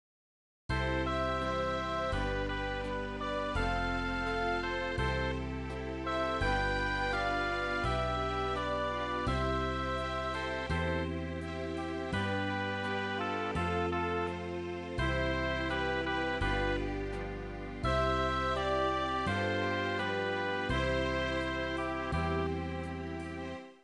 Walzer Shereade